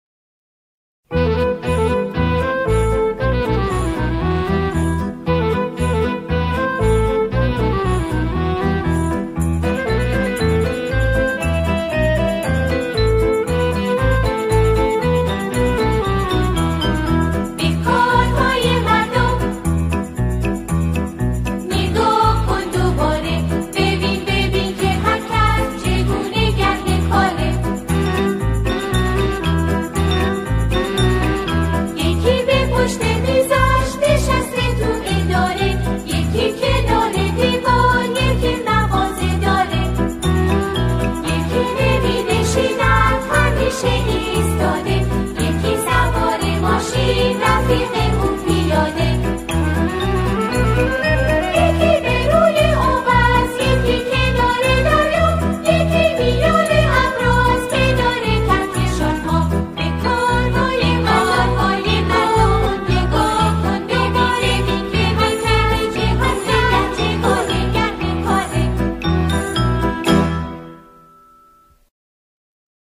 سرود کودکانه
را گروهی از جمعخوانان اجرا می‌کنند.